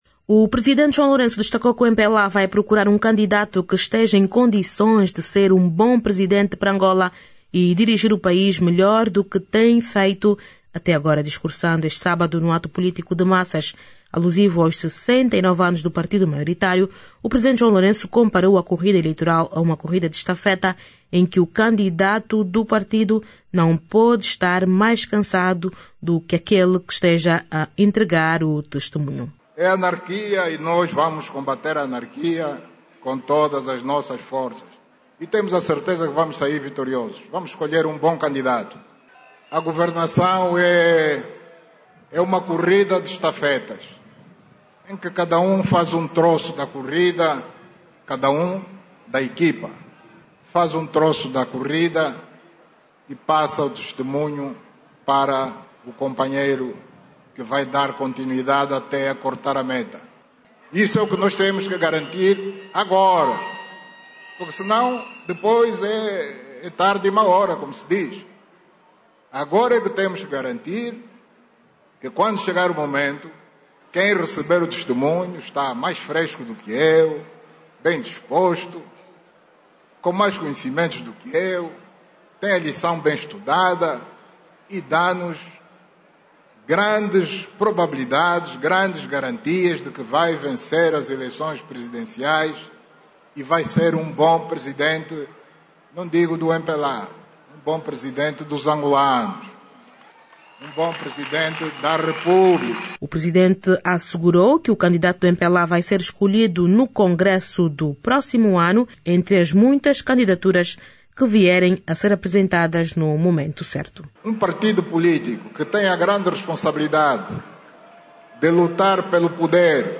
O Presidente do MPLA, João Lourenço, afirmou que o seu partido vai escolher um candidato á altura das exigências para assumir os destinos do país. João Lourenço, que falava no ato de massas que marcou os 69 anos de existência do MPLA, disse que o candidato do partido só vai ser conhecido no Congresso que acontece no próximo ano.